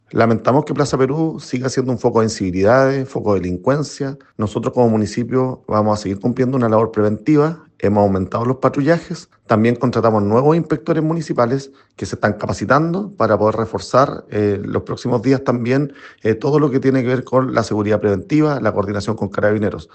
Al respecto, el alcalde de Concepción, dijo lamentar que este emblemático sector siga siendo un foco de incivilidades y de delincuencia. Según expuso, para hacer frente a esta situación, han aumentado los patrullajes y contrataron nuevos inspectores municipales, que se están capacitando, para sumarse a las labores de prevención y coordinación con Carabineros.
alcalde-plaza-peru.mp3